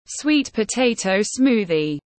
Sinh tố khoai lang tiếng anh gọi là sweet potato smoothie, phiên âm tiếng anh đọc là /swiːt pəˈteɪ.təʊ ˈsmuː.ði/
Sweet potato smoothie /swiːt pəˈteɪ.təʊ ˈsmuː.ði/